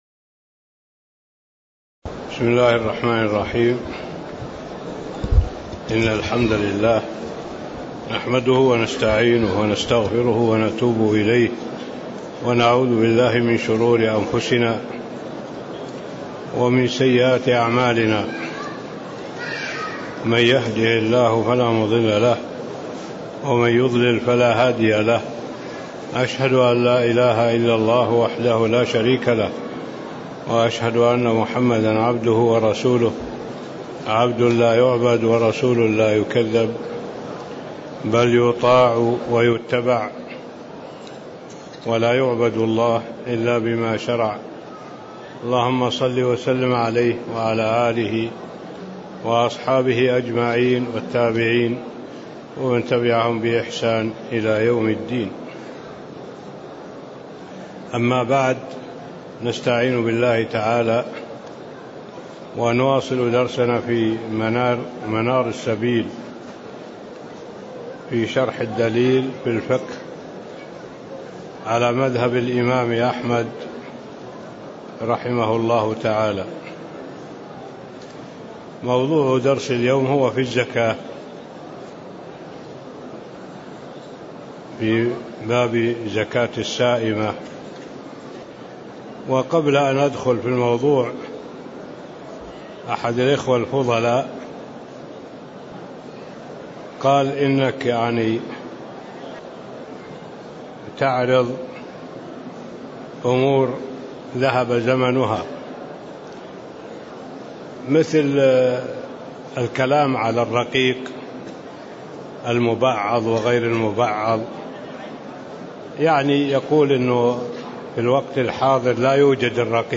تاريخ النشر ٧ شعبان ١٤٣٦ هـ المكان: المسجد النبوي الشيخ